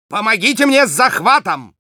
Голосовые команды шпиона - Official TF2 Wiki | Official Team Fortress Wiki